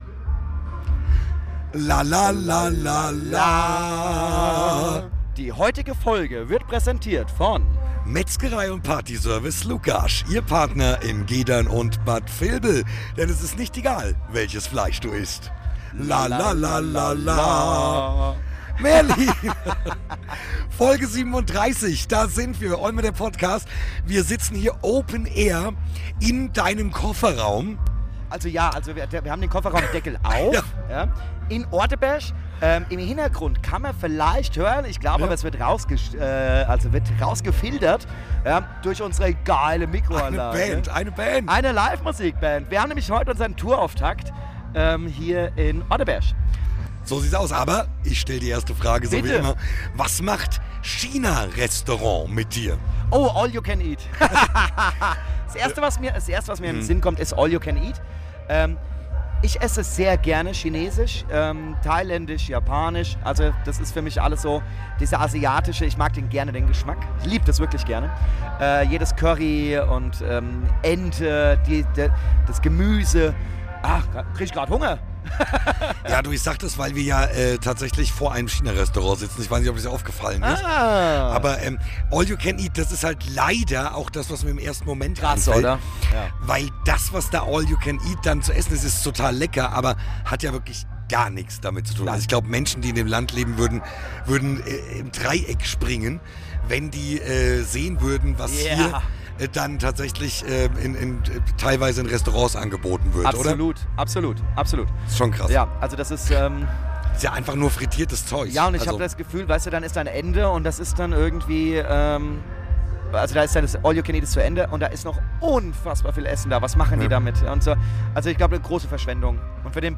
Spoiler: Es wird herzhaft gelacht! Während sie ihre Aufregung vor dem Tourauftakt teilen, gibt's spannende Geschichten über Polizei-Interaktionen und die Herausforderungen des Straßenverkehrs.